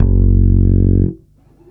8-F#1.wav